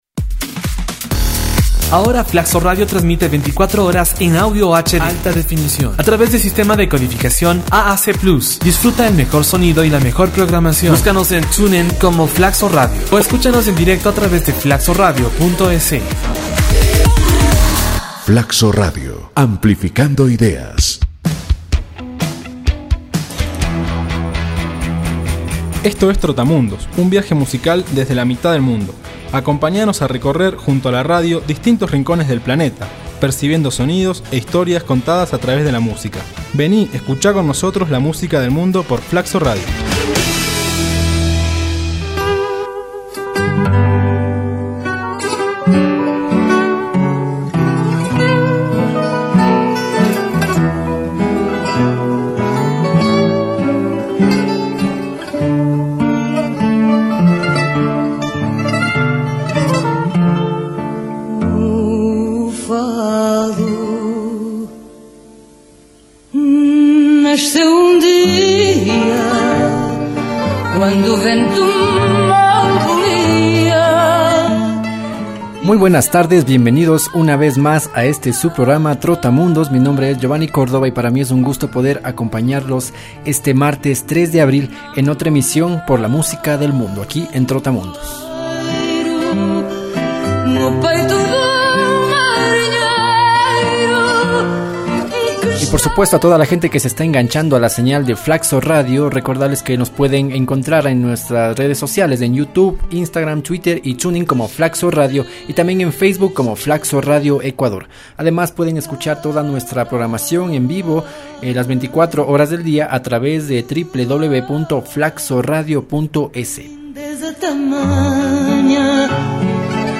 La cultura de Portugal se transmite a través de su música compuesta por los sonido de instrumentos típicos como el cavaquinho, la gaita, el acordeón, el violín, el tambor y la guitarra portuguesa, creando su ritmo más tradicional, el Fado.